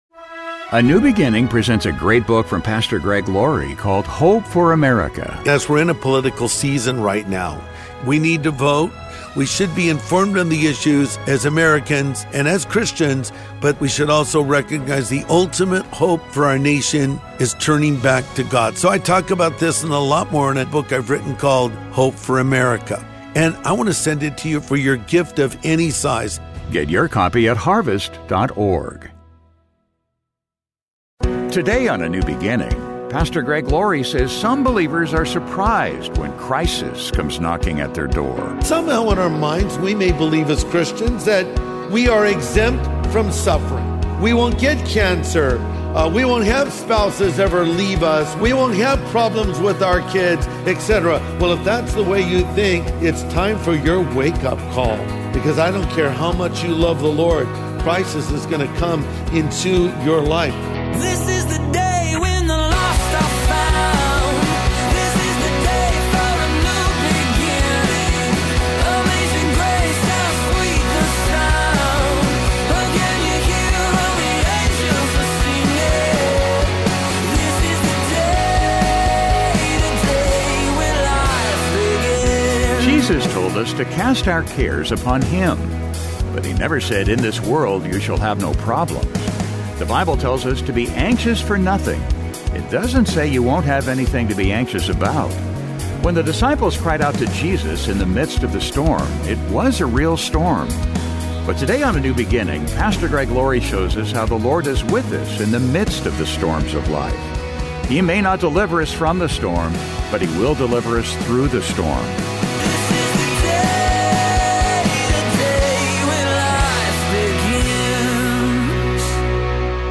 But today on A NEW BEGINNING, Pastor Greg Laurie shows us how the Lord is with us in the midst of the storms of life. He may not deliver us from the storm but He will deliver us through the storm.